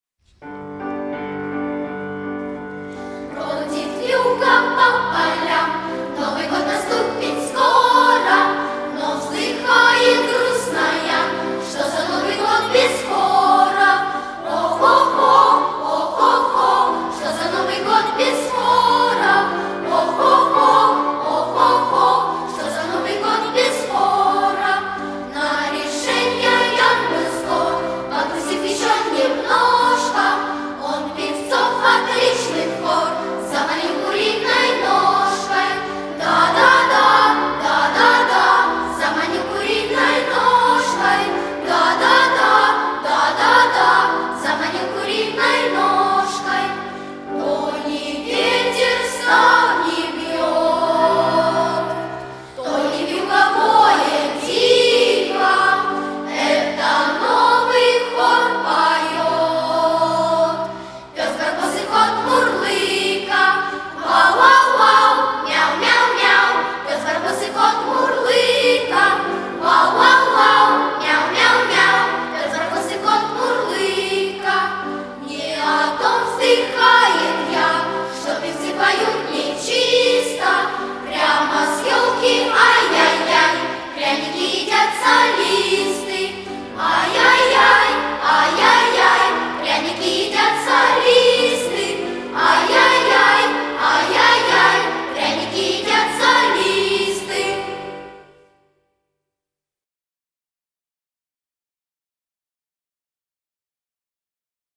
анс.Гармония-Эстонская народная песня - Хор нашего Яна.mp3